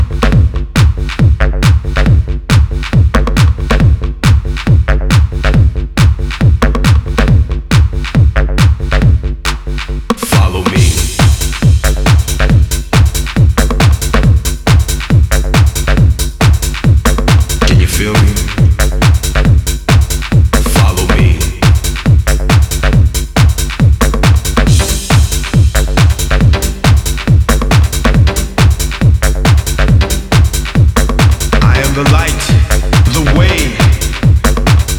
Жанр: Танцевальные / Электроника / Техно / Транс